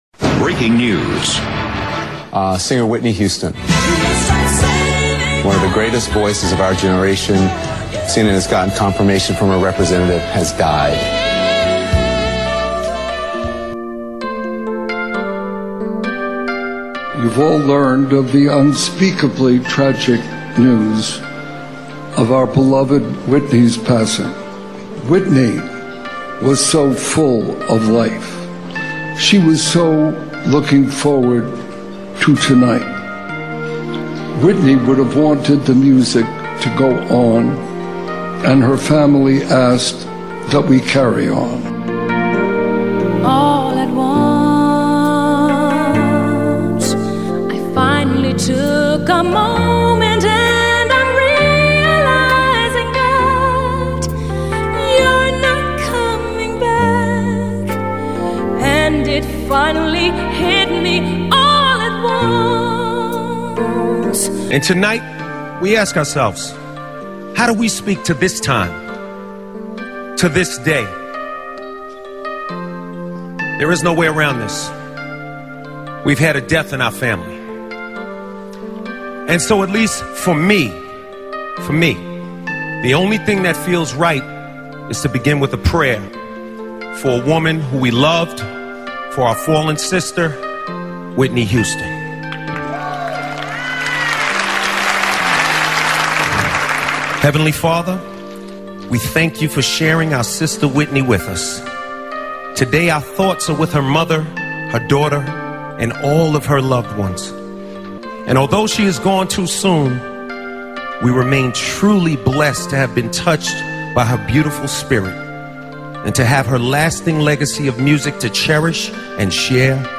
a special musical tribute